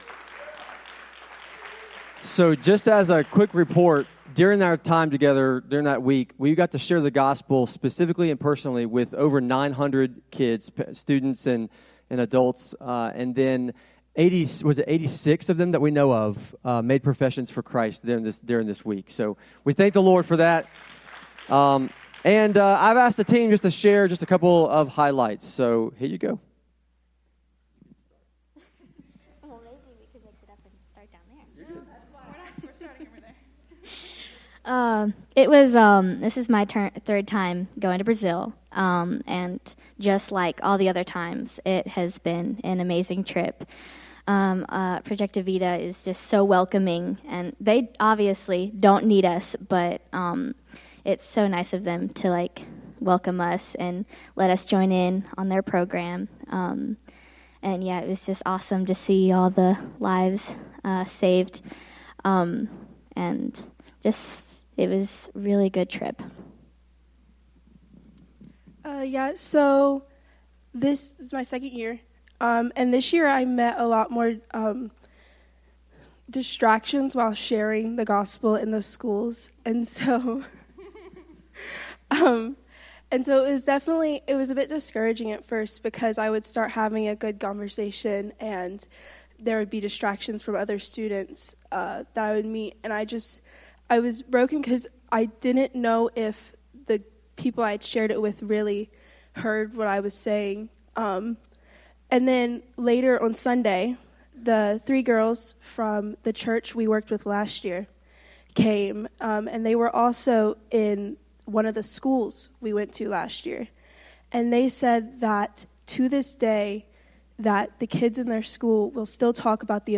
sermon-audio-trimmed-1.mp3